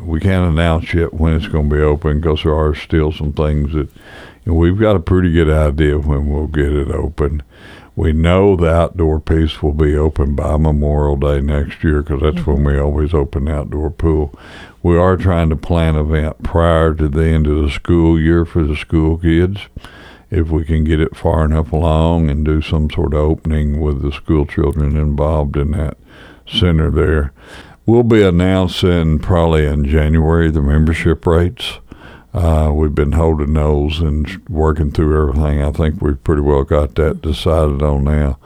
KTLO, Classic Hits and The Boot News spoke with Mayor Hillrey Adams, who says they expect the center to open in the spring, with the announcement of the long-awaited membership rates to be announced in January.